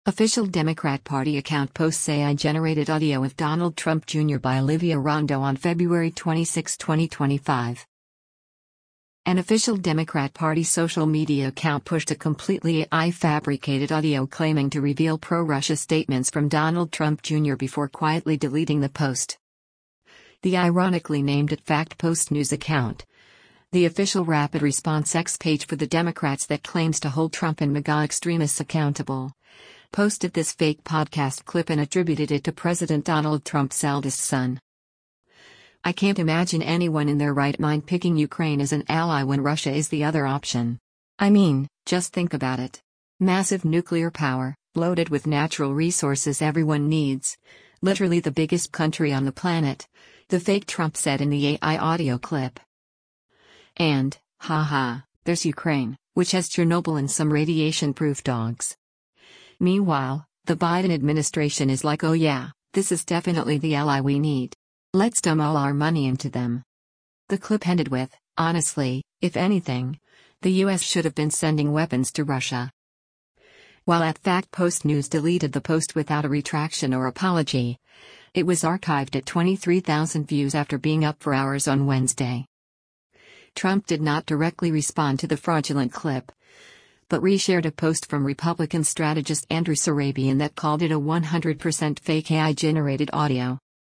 Official Democrat Party Account Posts AI-Generated ‘Audio’ of Donald Trump Jr.
An official Democrat Party social media account pushed a completely AI-fabricated audio claiming to reveal pro-Russia statements from Donald Trump, Jr. before quietly deleting the post.